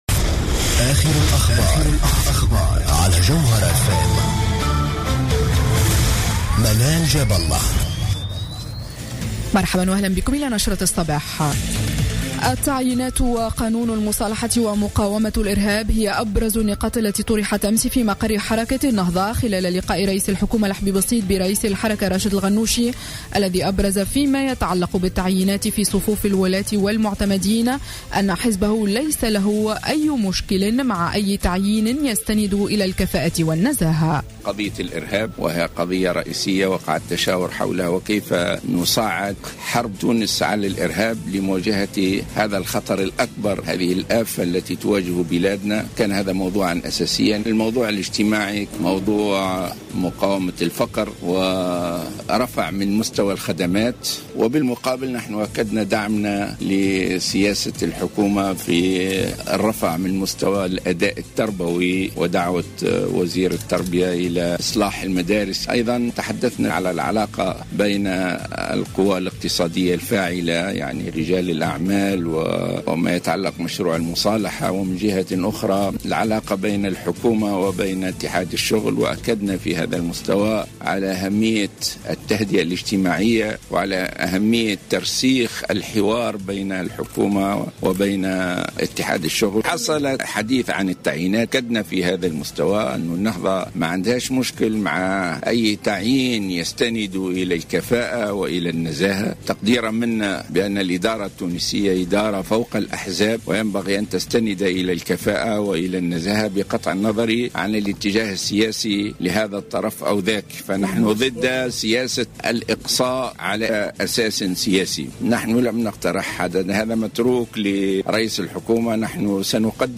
نشرة أخبار السابعة صباحا ليوم السبت 08 أوت 2015